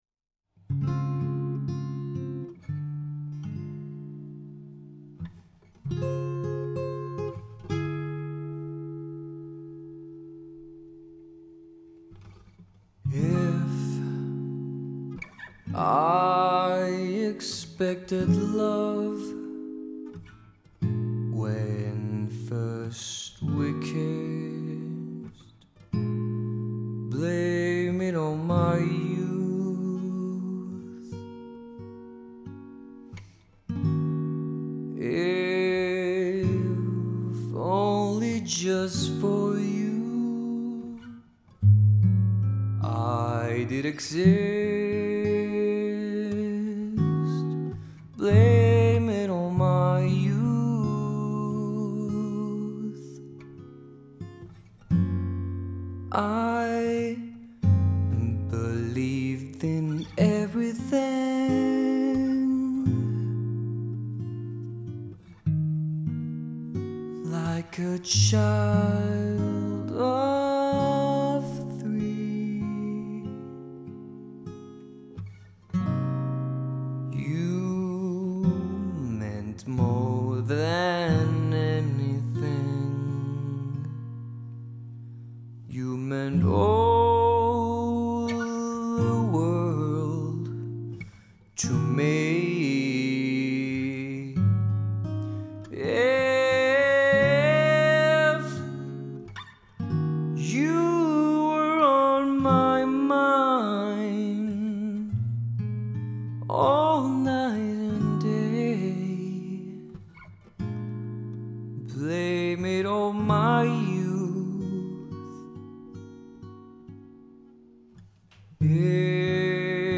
con errores y todo.. como debe de ser
Guild GAD25